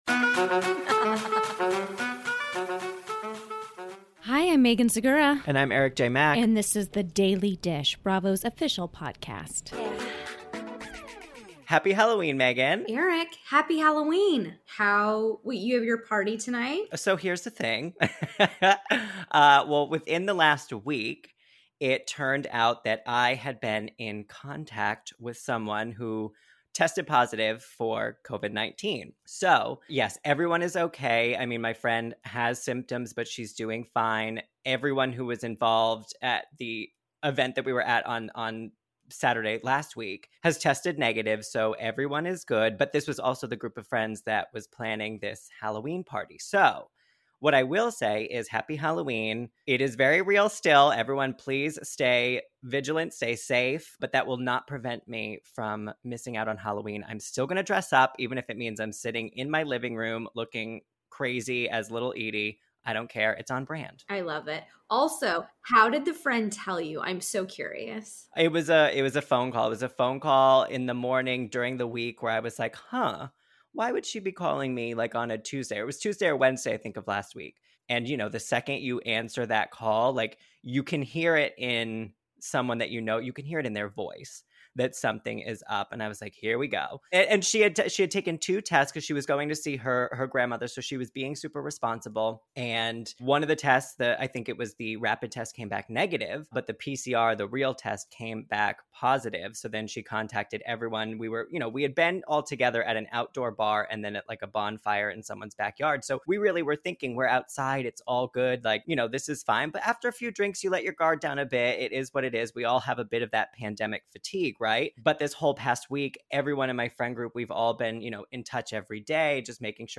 Plus, Craig Conover calls in to discuss the return of Southern Charm, including the shocking revelation about Kathryn Dennis' living situation and updates to his dating life.